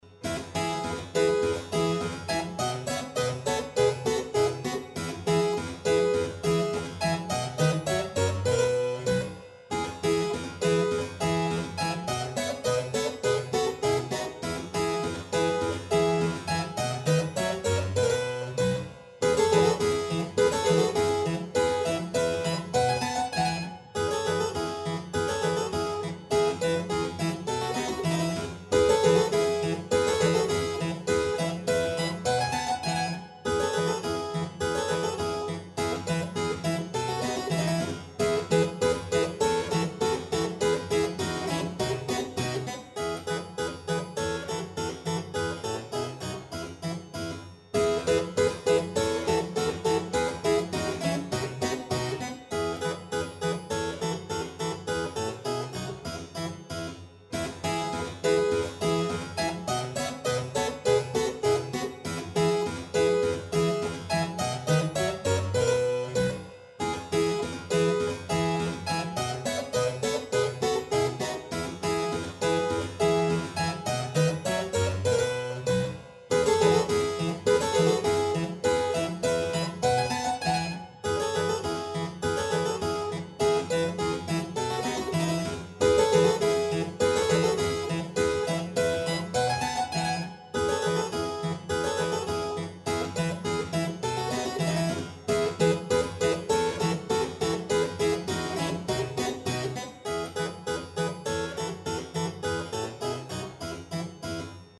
Als Beispiel für einen 'Englischen' ein Contretanz von Joseph Haydn (1732-1809)
anglaise.mp3